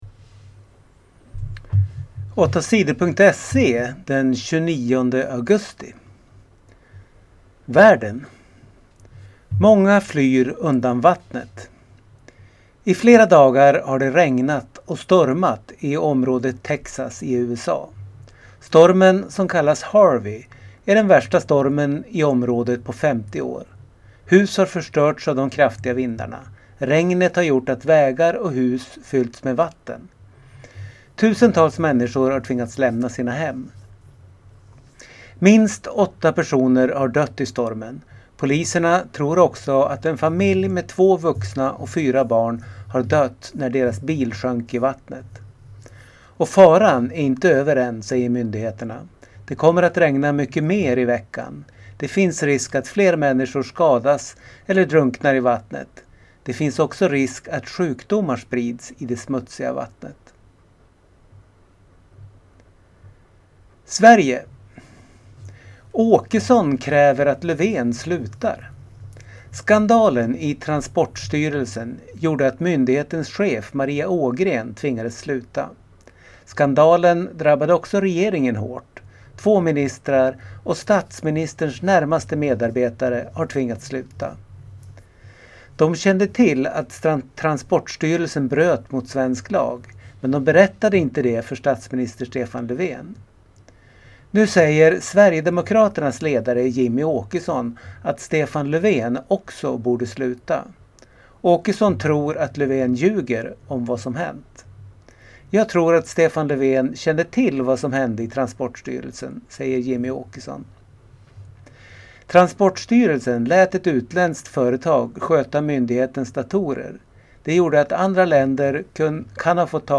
Lyssna på nyheter från tisdagen den 29 augusti